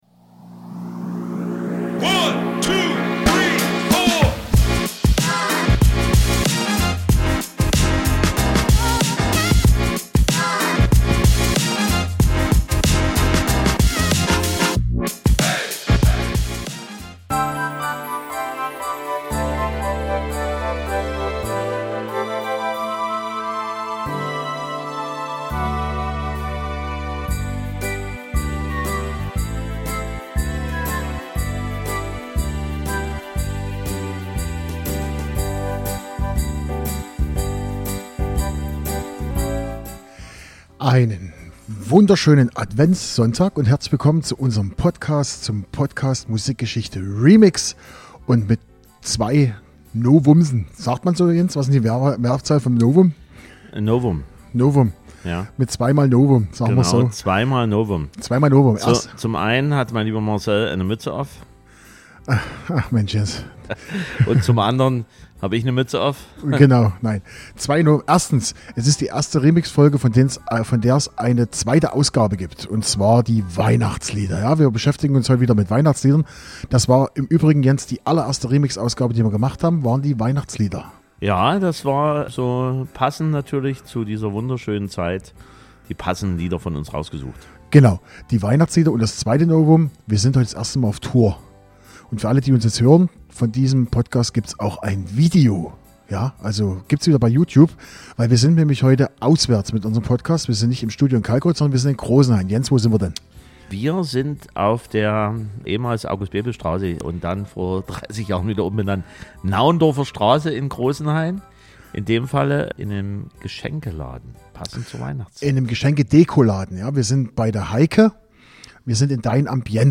Eins - wir sind zum ersten Mal mit unserem Podcast nicht im Kalkreuther Keller, sondern wir haben im kleinen, aber feinen Geschenke- & Dekoladen "Dein Ambiente" in Großenhain aufgenommen. Zwei - zum ersten Mal gibt es eine zweite Folge einer Remix-Ausgabe.